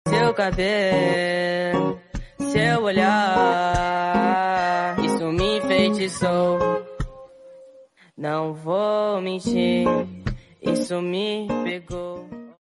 Reggae Remix